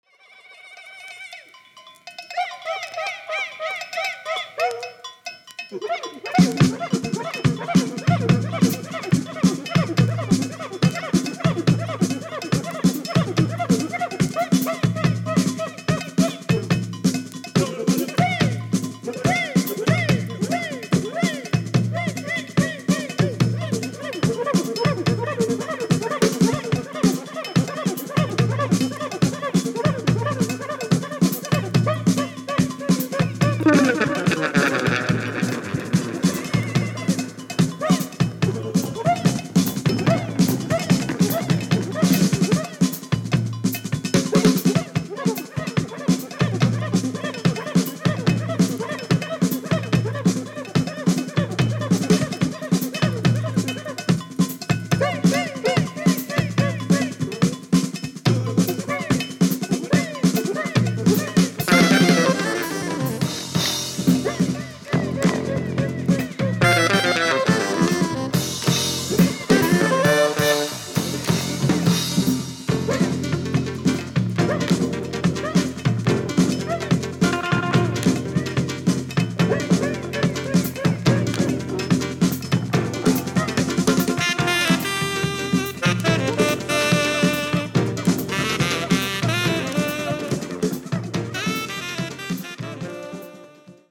Voice ,Percussion
Electric Bass
Drums